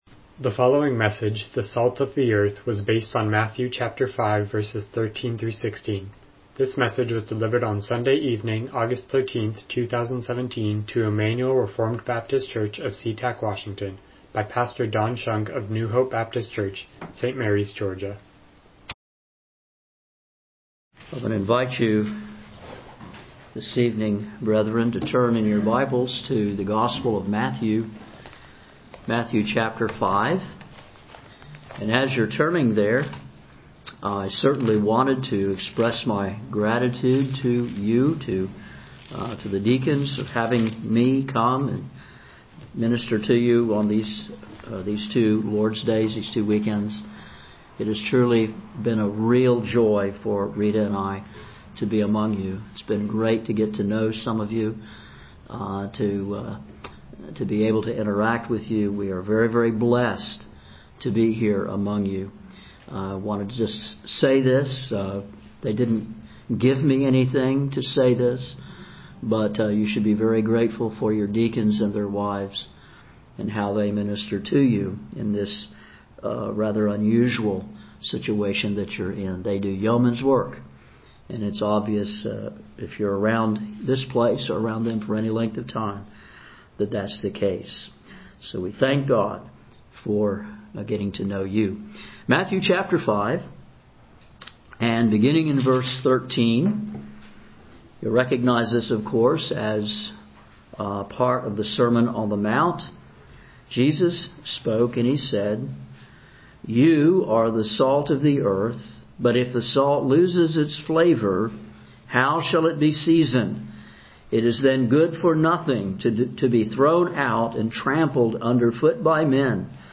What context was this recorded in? Matthew 5:13-16 Service Type: Evening Worship « The Incredible Love of Christ Church Membership